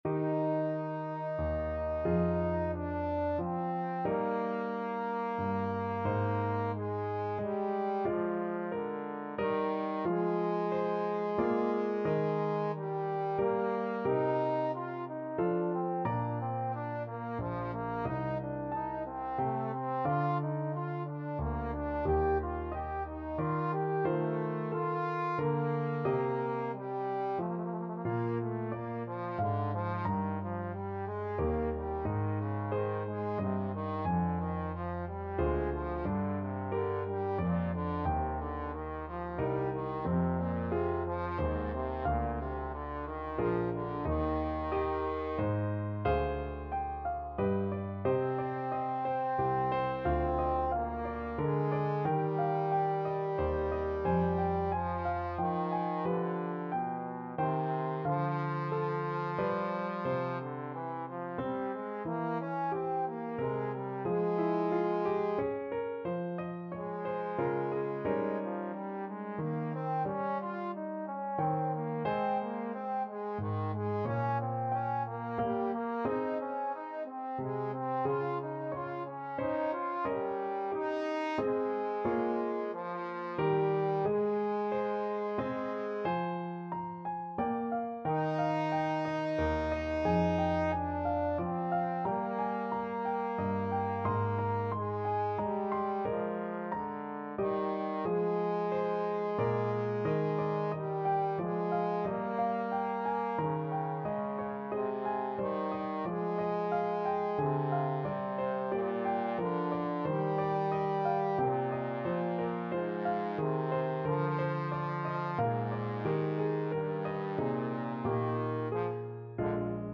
3/4 (View more 3/4 Music)
G3-G5
Largo ma non tanto ( = c. 90)
Classical (View more Classical Trombone Music)